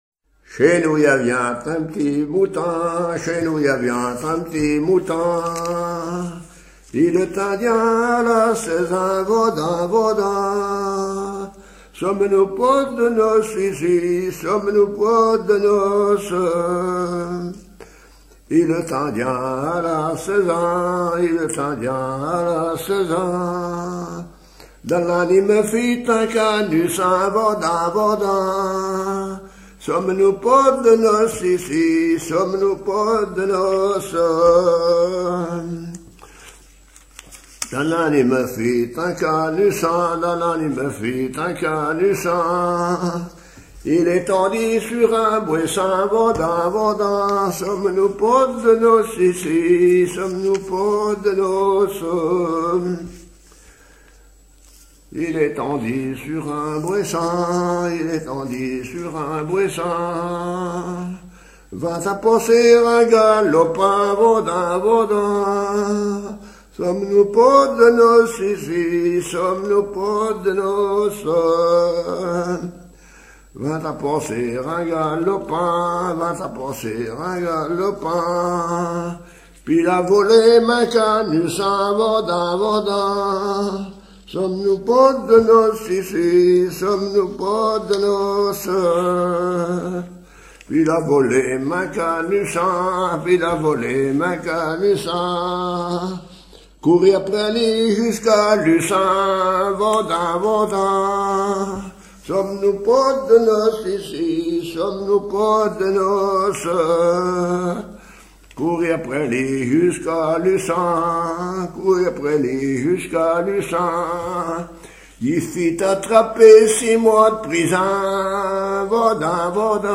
Mémoires et Patrimoines vivants - RaddO est une base de données d'archives iconographiques et sonores.
Catégorie Pièce musicale éditée